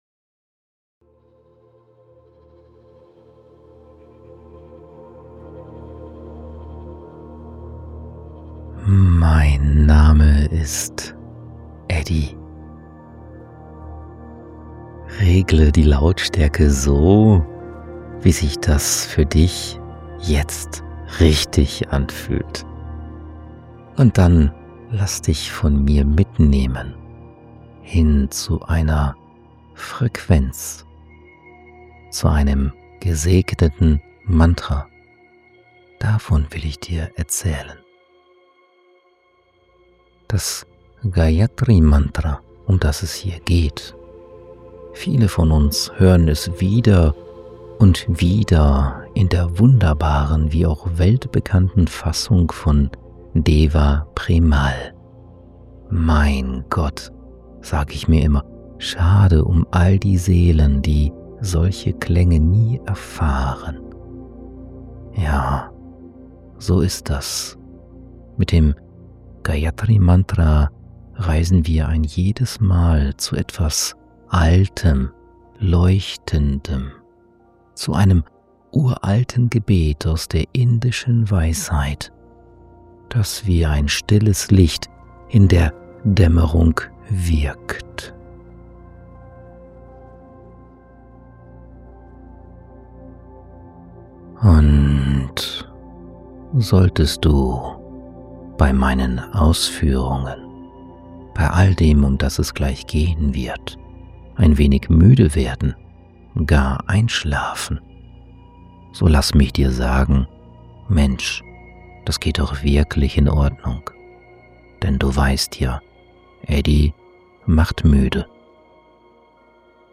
Vom Gayatri Mantra erzähle ich dir freundlich, müde machend. Wir reisen zu etwas Altem, Leuchtendem – zu einem uralten Gebet aus der indischen Weisheit, das wie ein stilles Licht in der Dämmerung wirkt.